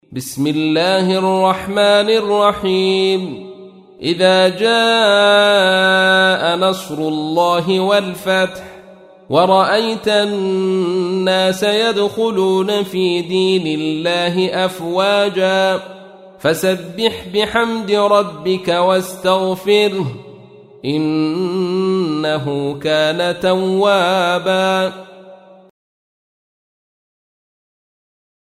تحميل : 110. سورة النصر / القارئ عبد الرشيد صوفي / القرآن الكريم / موقع يا حسين